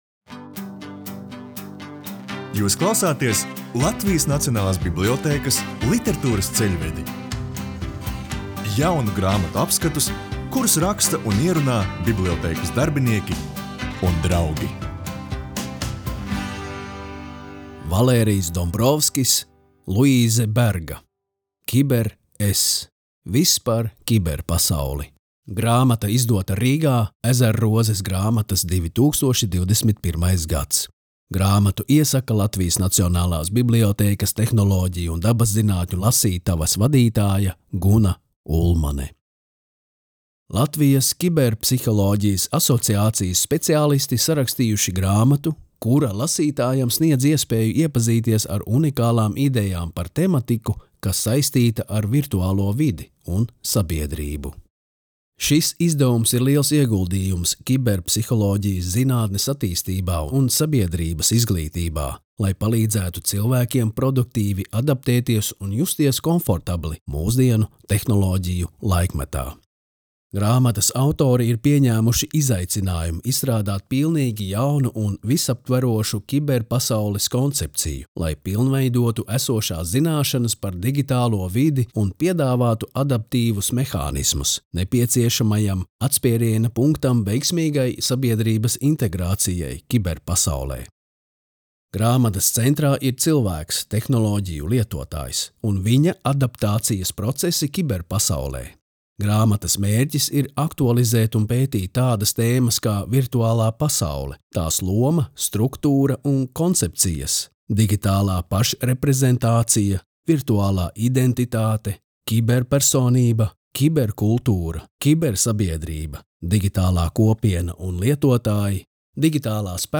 Latvijas Nacionālās bibliotēkas audio studijas ieraksti (Kolekcija)